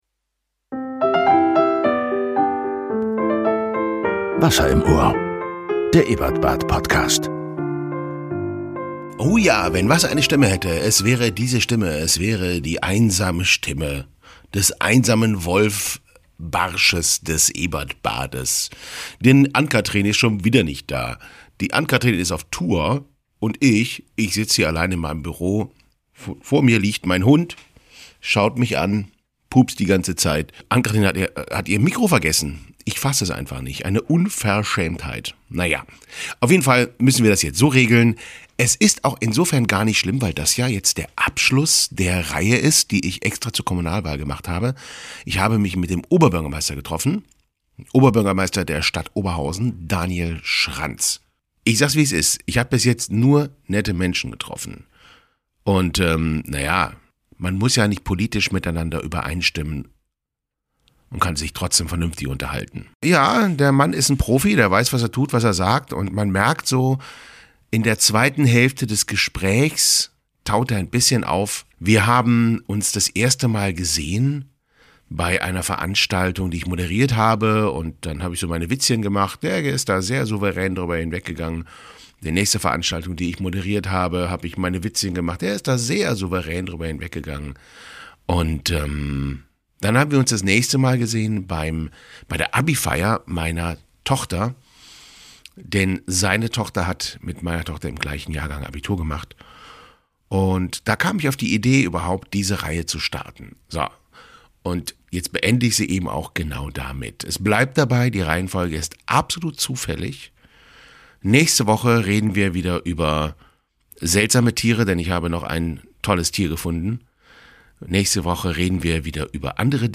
Im Interview: Oberbürgermeister Daniel Schranz CDU, in dieser Reihe möchte ich die Spitzenkandidaten der im Stadtrat vertretenen Parteien vorstellen.